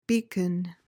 beacon.mp3